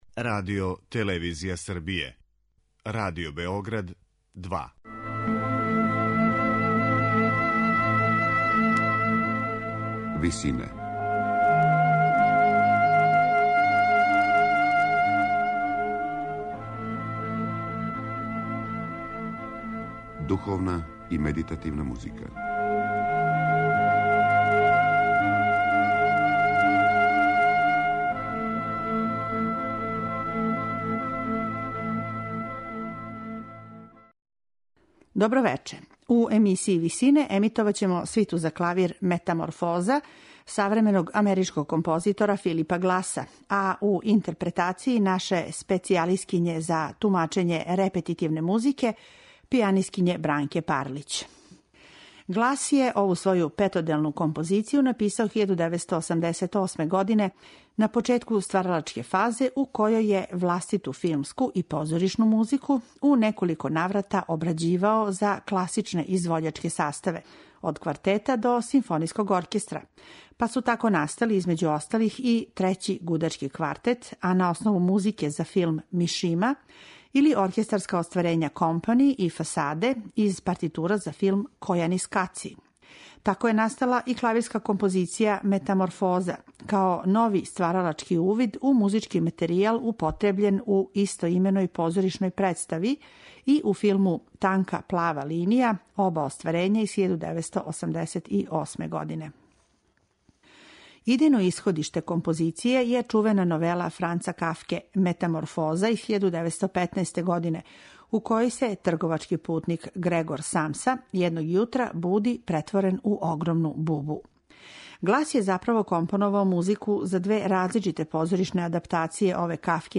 клавирску свиту